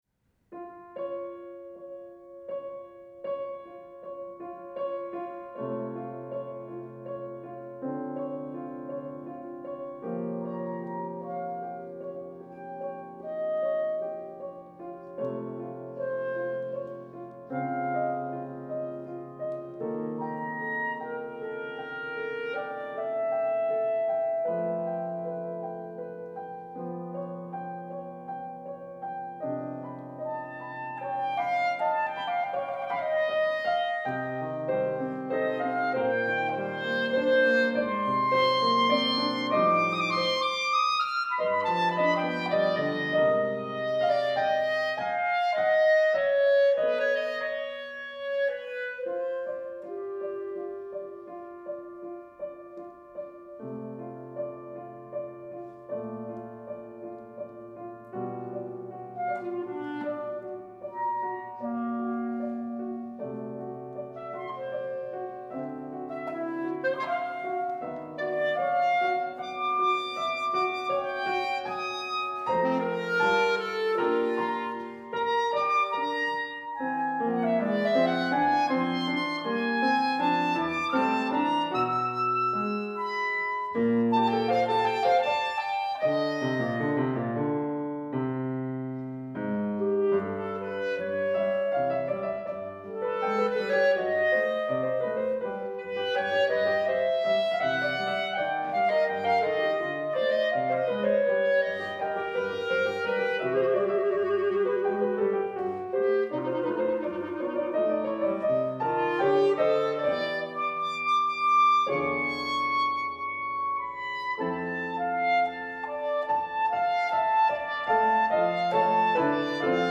The Marriage of Figaros for clarinet and piano, 2015